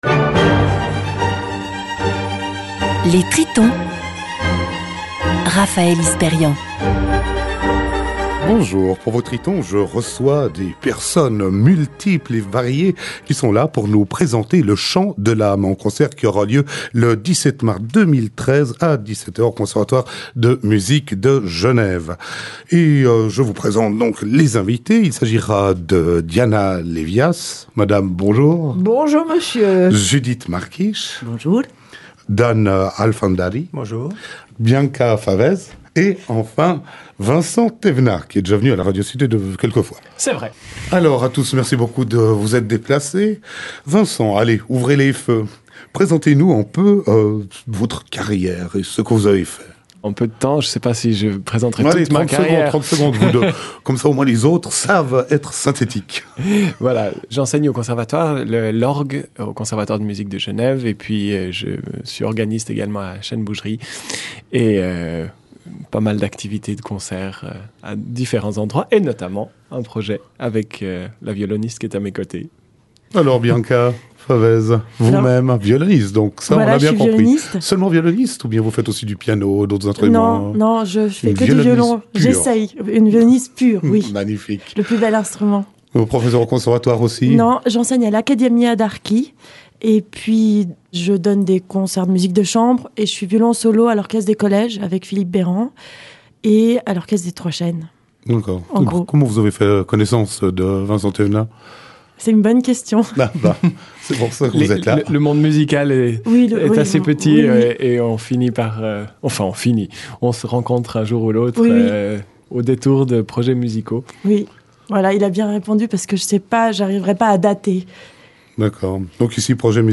CONCERT � 17h Conservatoire de Musique de Gen�ve Place Neuve - Gen�ve
violon
orgue
Des improvisations originales � l'orgue entrent en dialogue et cr�ent un lien entre ces �uvres musicales qui feront r�sonner l'�me de chacun de nous.